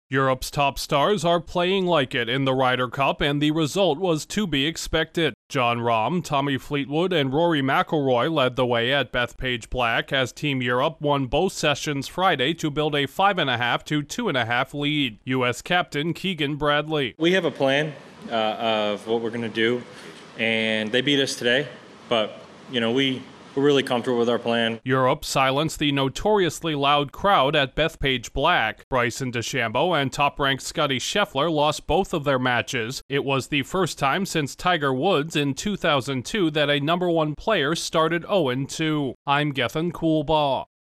Europe’s best players are shining while America's are struggling after day one at the Ryder Cup. Correspondent